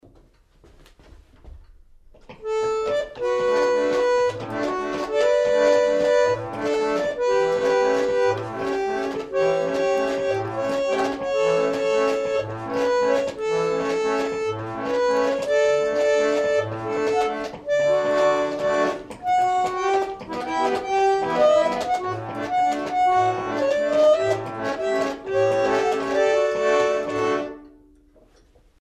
melodion 1800
Esempio audio: Musestunden waltz (C.M. Ziehrer) Melodion (organo a canne viennese) del 19mo secolo
melodion.mp3